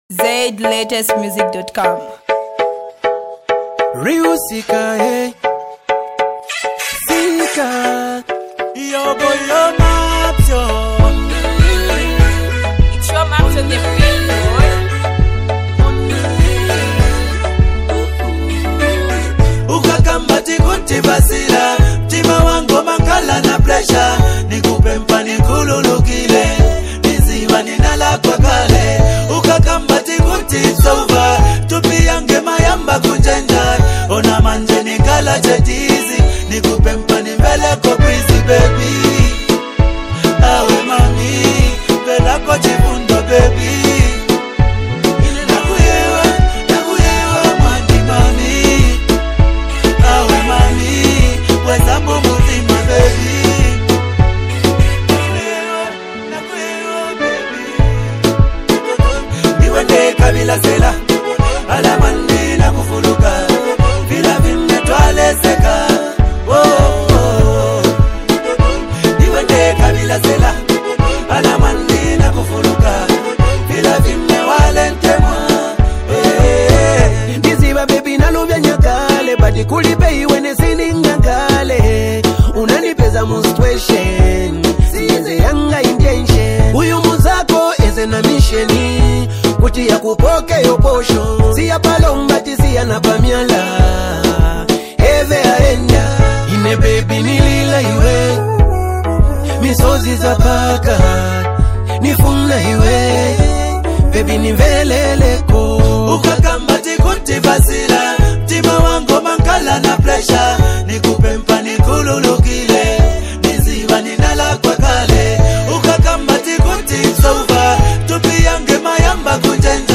MusicZambian Music 2025
adds his signature soulful vocals and emotional depth